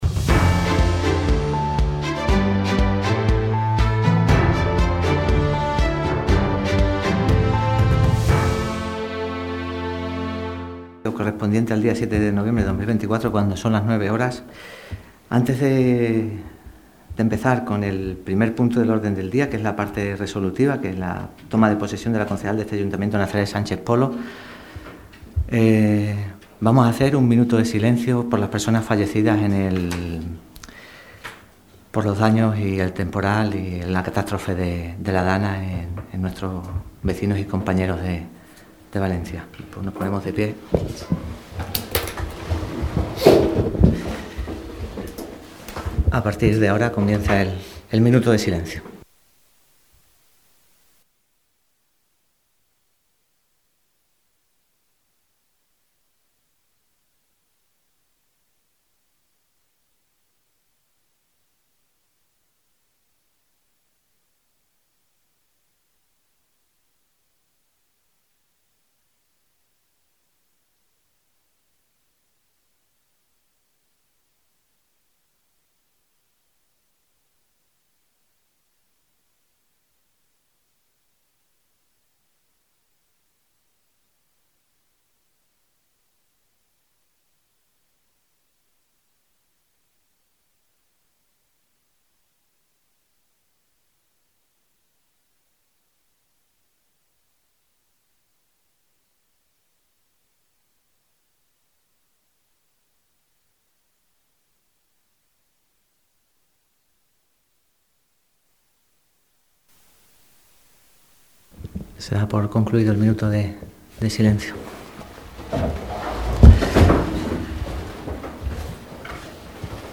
Sesión EXTRAORDINARIA de Pleno, 07 de Noviembre de 2024 (A) - radio Guareña